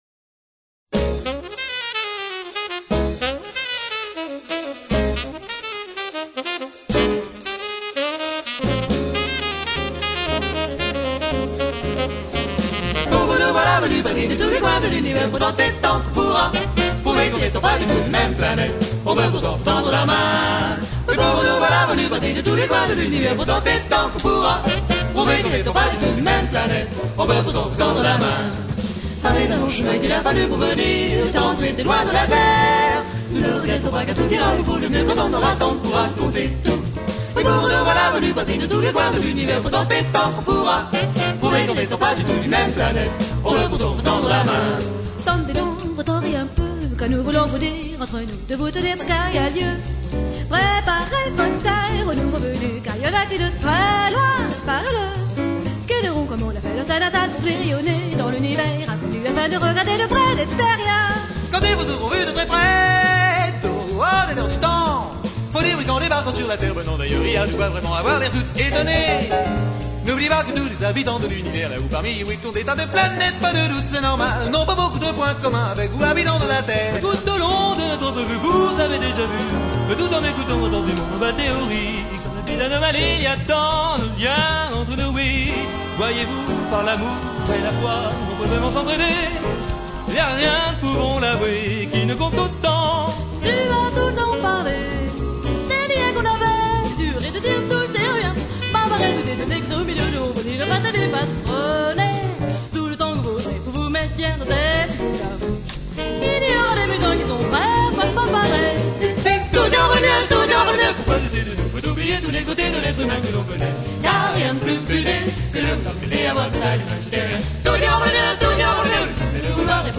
Les chanteurs du premier quatuor étaient :